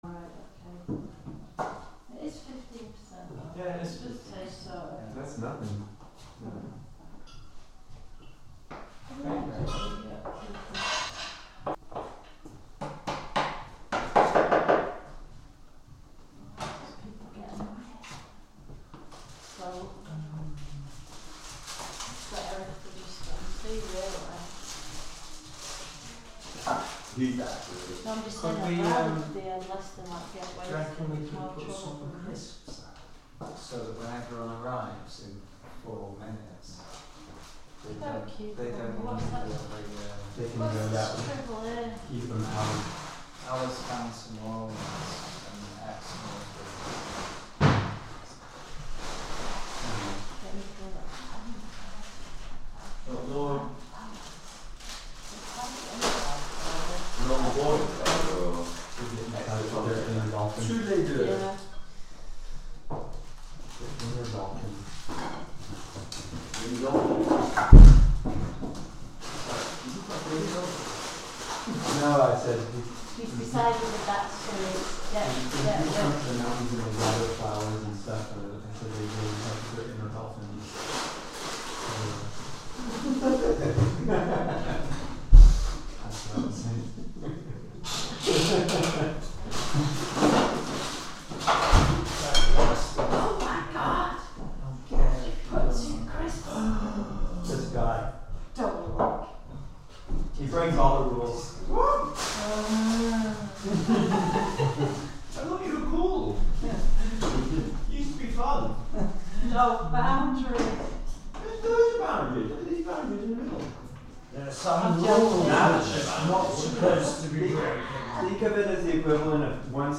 Live from Soundcamp: Soundcamp radio (Audio) Oct 15, 2023 shows Live from Soundcamp Live transmissions by the Soundcamp cooperative and the Channelsea ~ Spree Radio Group.